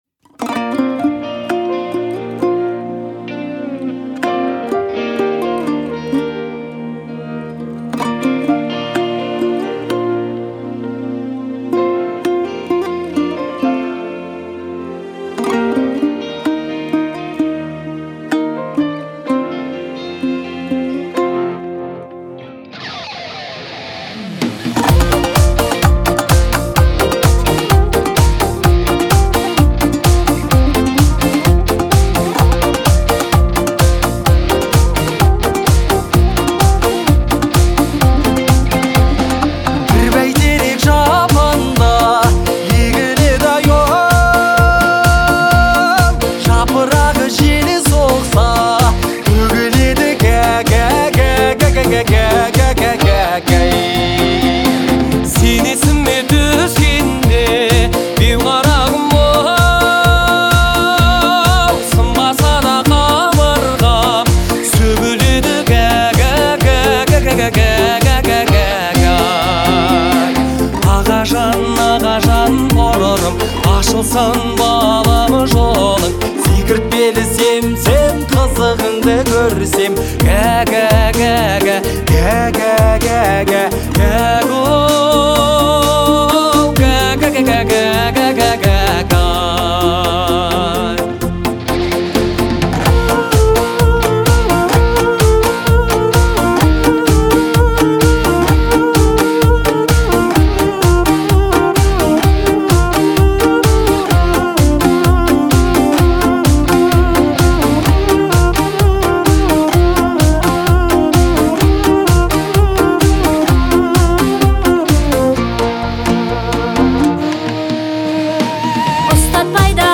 это трогательное произведение в жанре народной музыки